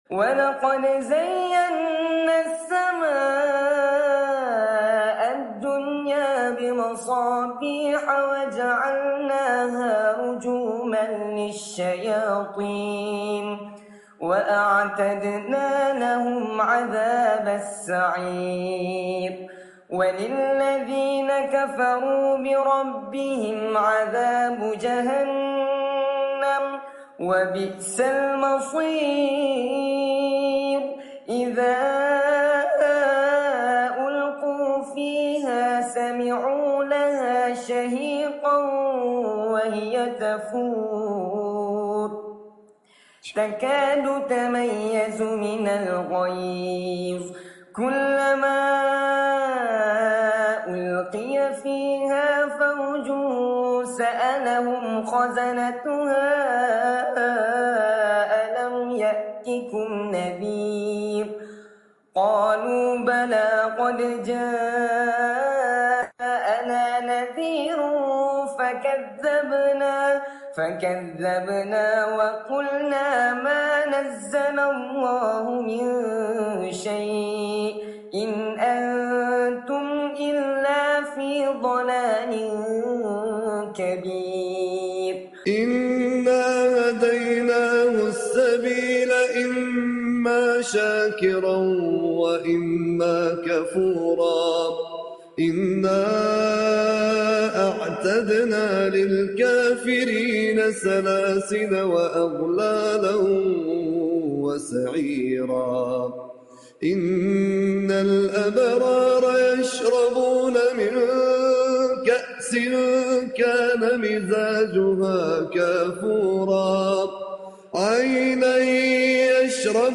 Ayat dari Surat Al-Mulk Dalam live